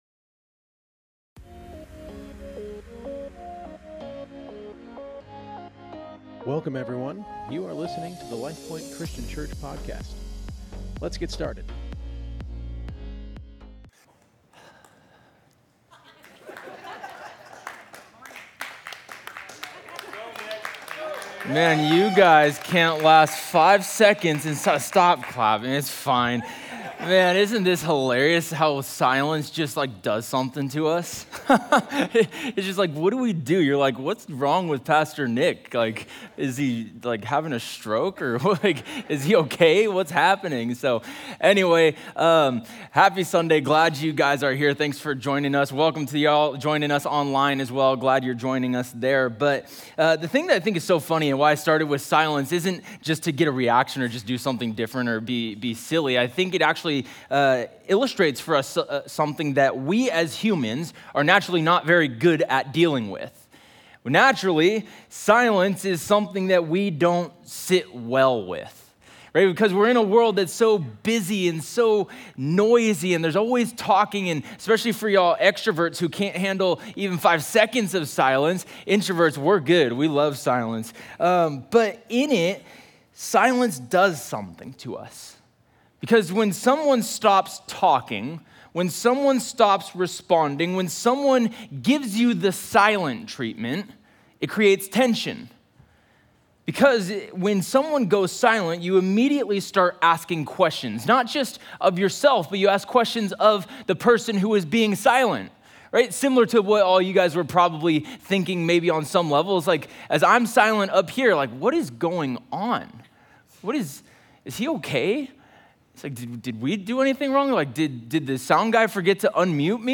Subscribe to receive notification when new sermons are posted!